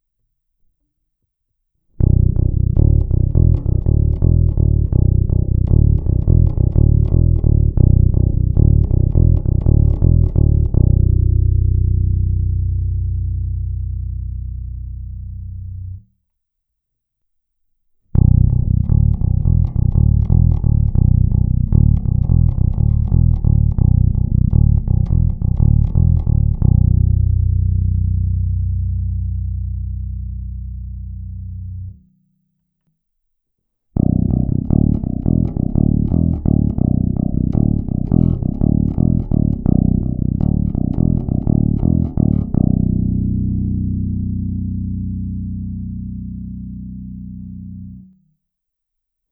Zvuk má modernější projev, je pěkně tučný, se sametovými nižšími středy, ovšem taky je nepatrně zastřený díky použitým humbuckerům.
Není-li uvedeno jinak, následující nahrávky jsou provedeny rovnou do zvukové karty, s plně otevřenou tónovou clonou a na korekcích jsem trochu přidal jak basy, tak výšky.